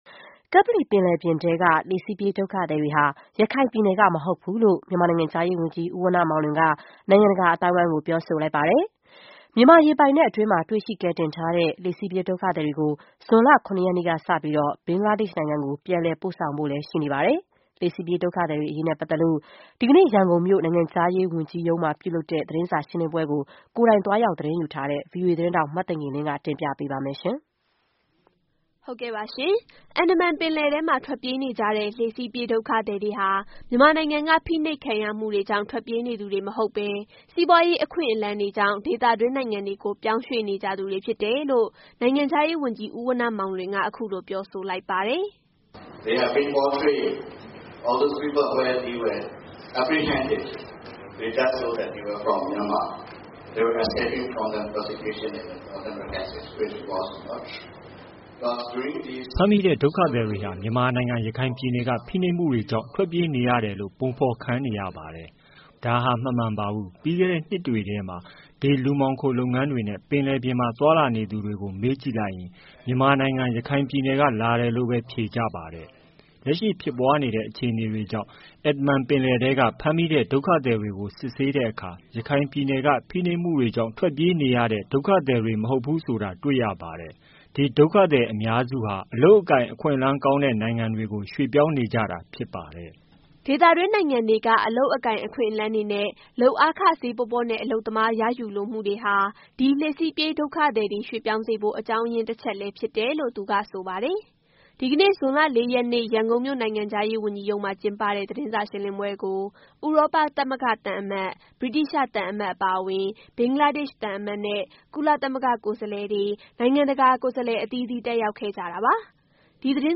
နိုင်ငံခြားရေးဝန်ကြီးဌာန သတင်းစာရှင်းလင်းပွဲ။